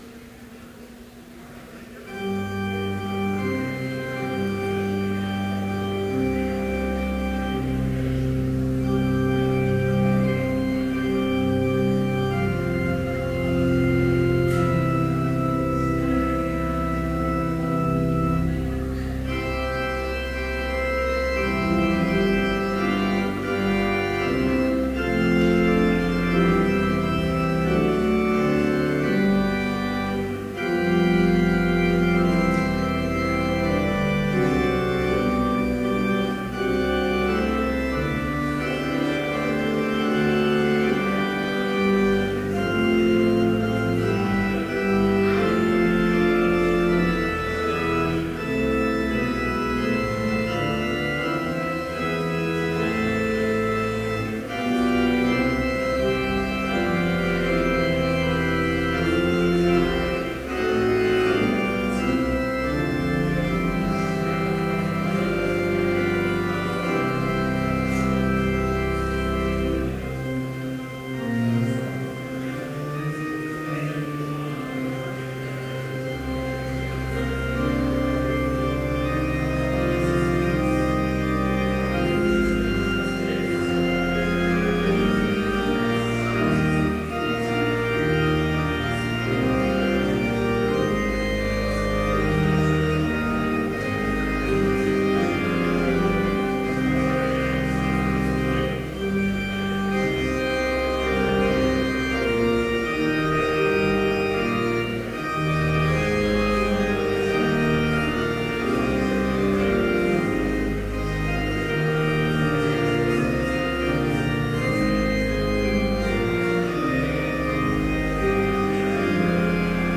Complete service audio for Chapel - January 31, 2014